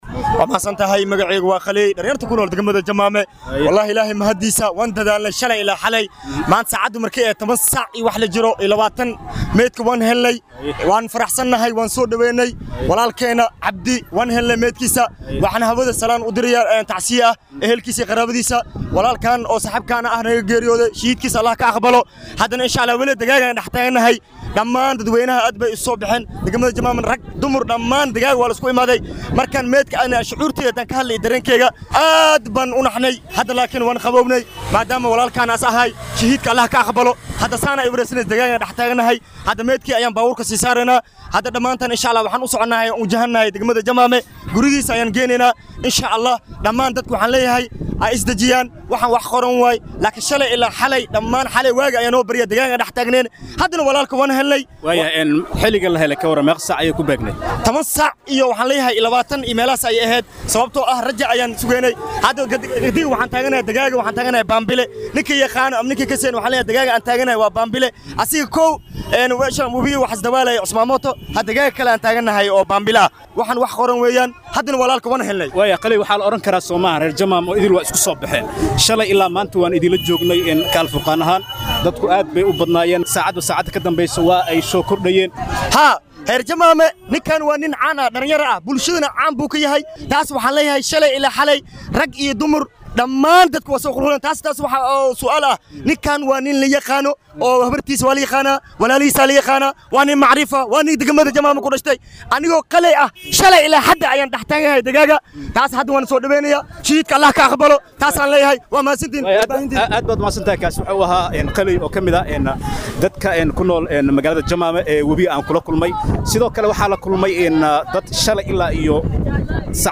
Qaar kamid ah ehelada wiilka iyo dad shalay xalay ilaa maanta ku sugnaa wabiga ayaan wareysiyo la yeelanay.